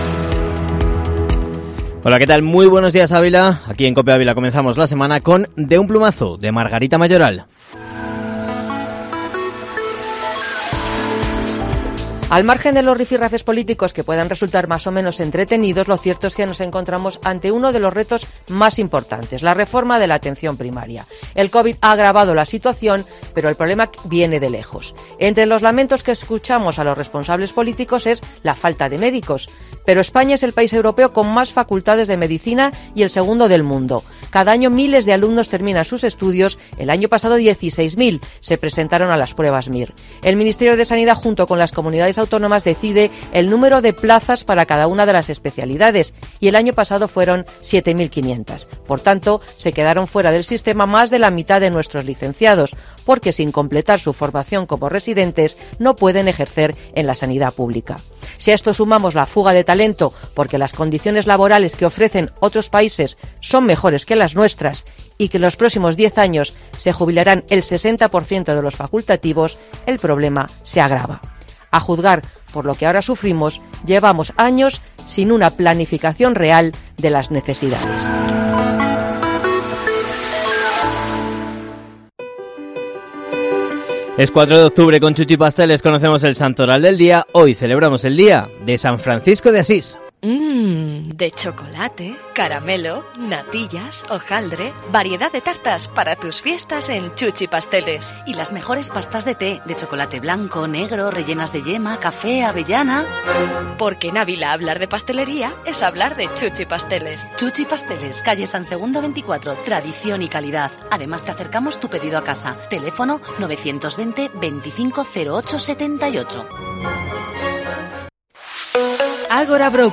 Informativo Matinal Herrera en COPE Ávila -4-oct